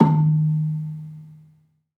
Gambang-D#2-f.wav